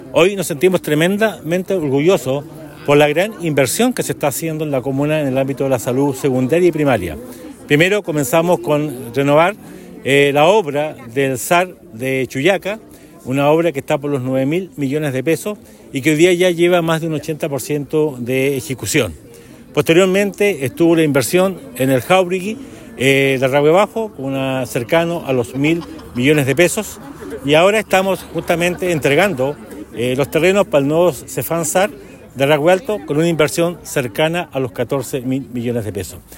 El jefe comunal, también destacó la inversión en materia de salud que se está realizando en Osorno donde se suma el SAR de Chuyaca, la renovación de Cesfam Pedro Jauregui y el proyecto de un centro hospitalario para Rahue.